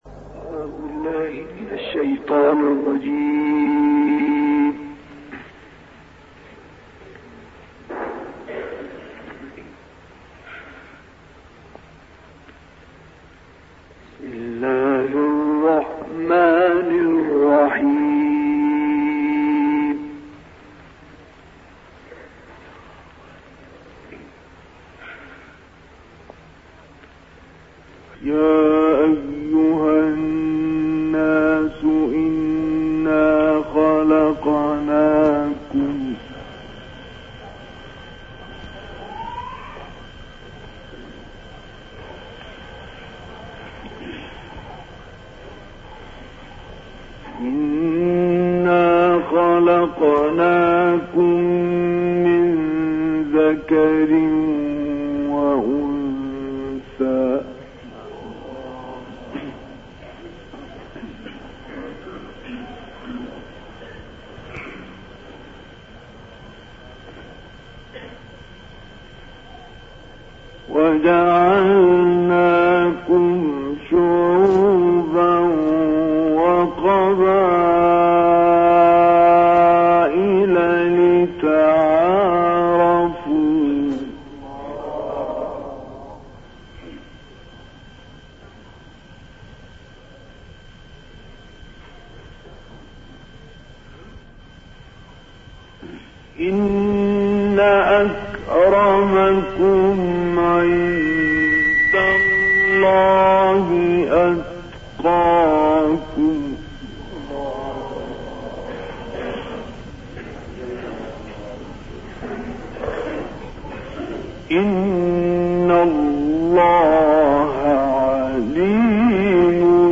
الحجرات و ق.. تلاوة بديعة للشيخ مصطفى اسماعيل في سوريا
القرآن الكريم - الكوثر: تلاوة عطرة من بدائع تلاوات القارئ الكبير الشيخ مصطفى إسماعيل من سور الحجرات(13-18) و ق(1-45) والشرح والاخلاص. تلاها عام 1957 في جامع الشيخ المغربي في اللاذقية.